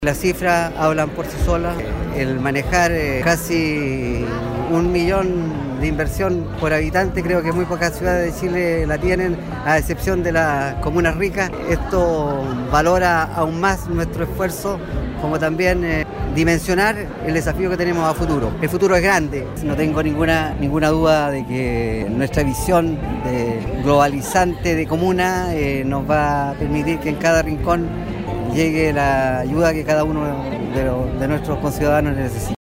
Ante una audiencia repleta de vecinos, dirigentes sociales, autoridades comunales, regionales y provinciales, el alcalde Ramón Bahamonde rindió su tercera cuenta pública correspondiente a la gestión del año 2018, destacando la buena salud del estado financiero del municipio que permitirá la concreción de importantes iniciativas en los próximos años.